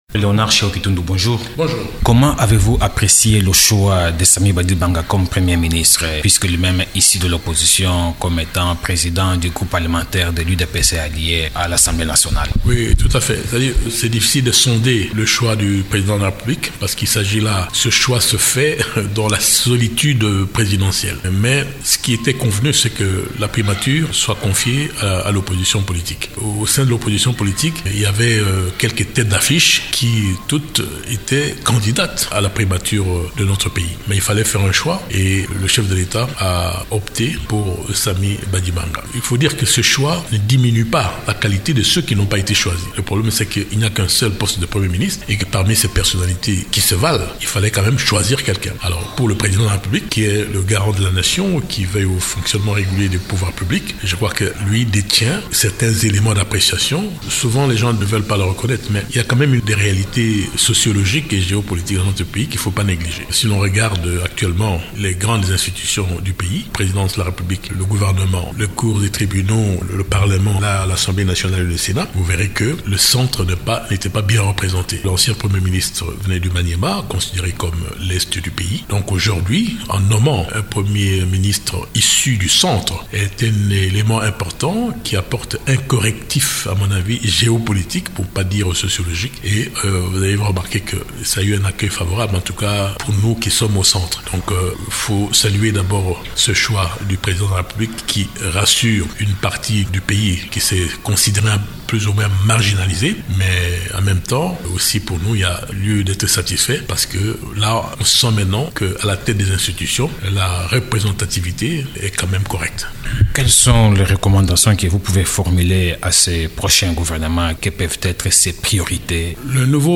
Invité de Radio Okapi ce mardi, She Okitundu, le porte-parole de la composante Majorité présidentielle au dialogue politique national considère cette nomination d'un correctif  à l’injustice qui règne dans la représentation des provinces congolaises au sein des institutions nationales.